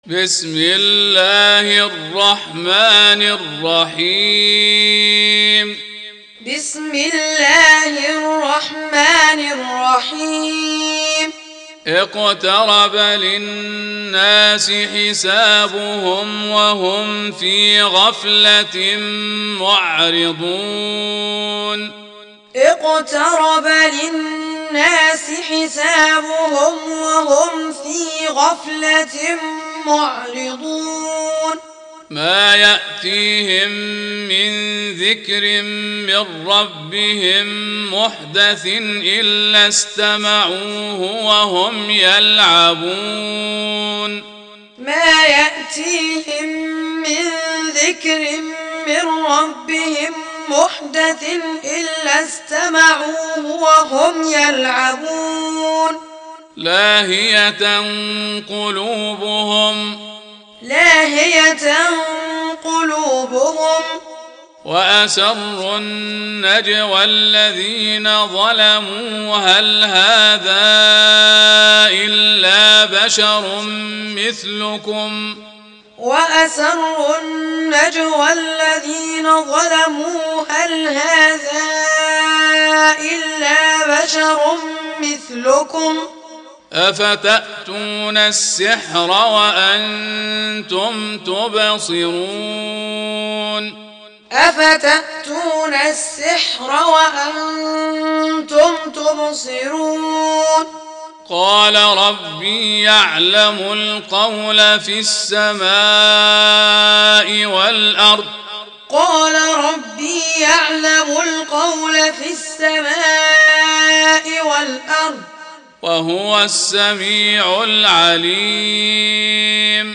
Taaleem Tutorial Recitation